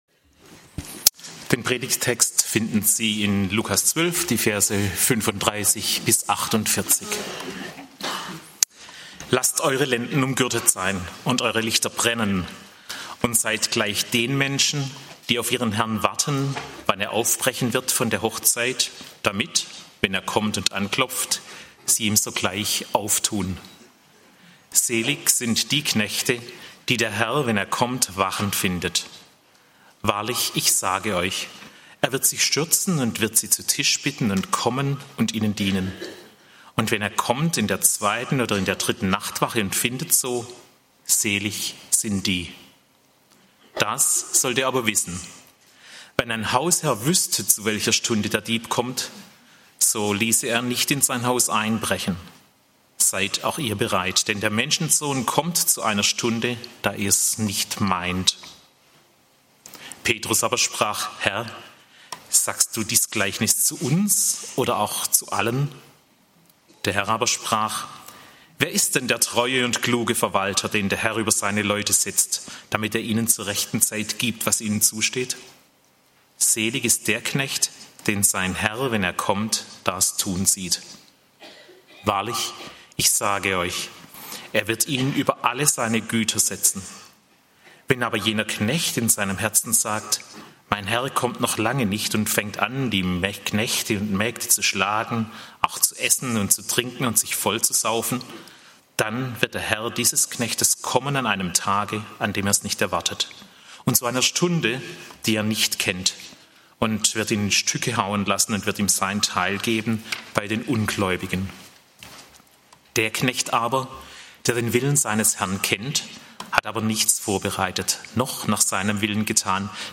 Wenn ER kommt! (Lk. 12, 35-48) - Gottesdienst